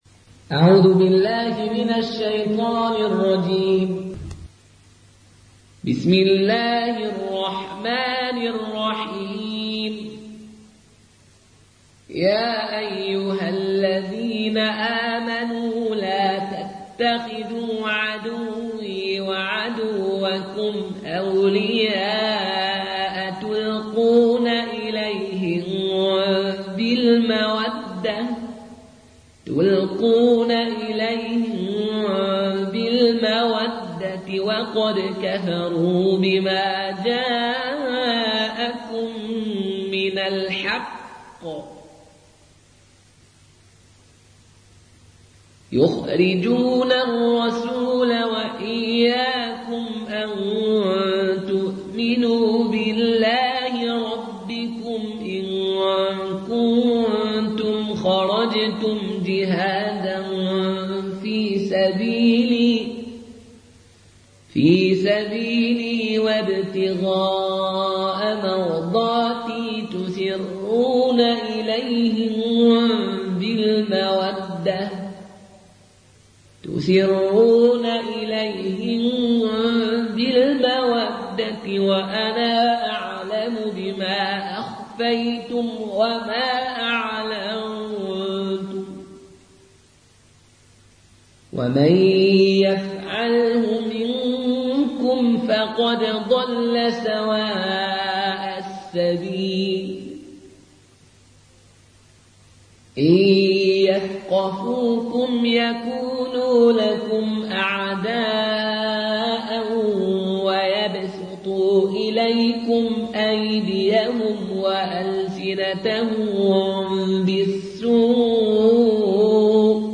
روایت فالون